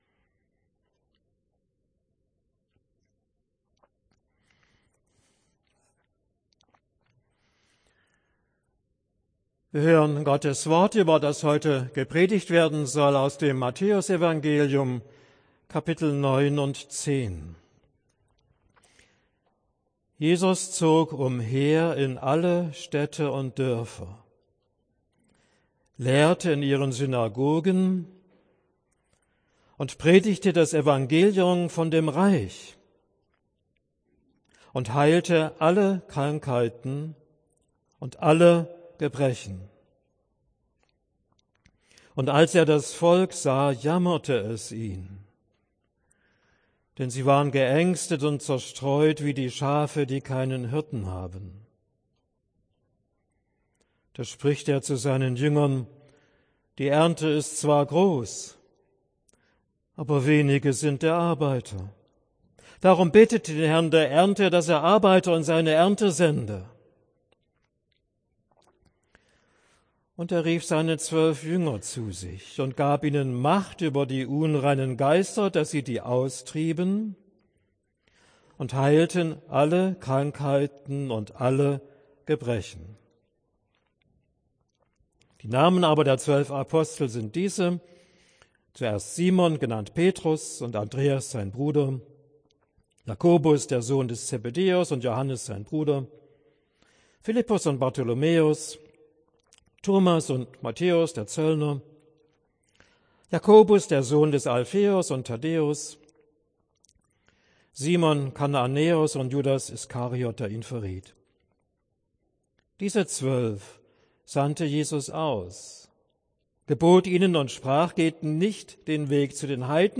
Predigt für den 5. Sonntag nach Trinitatis